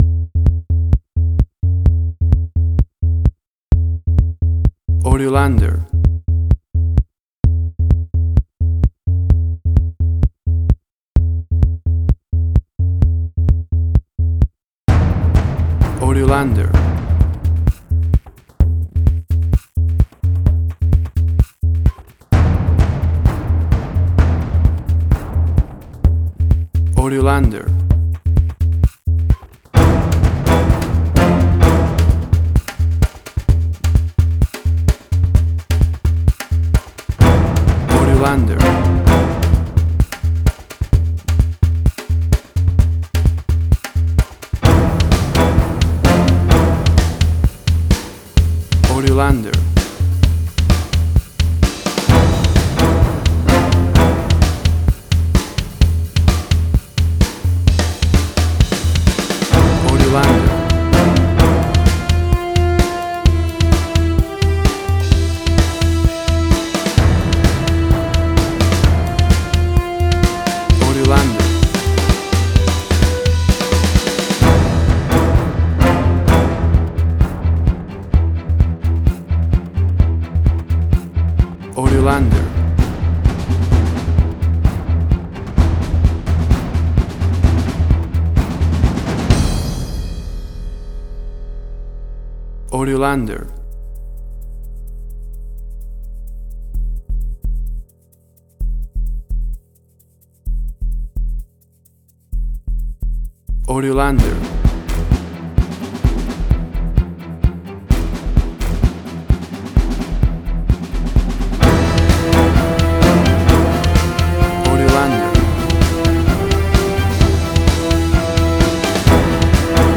Suspense, Drama, Quirky, Emotional.
WAV Sample Rate: 16-Bit stereo, 44.1 kHz
Tempo (BPM): 129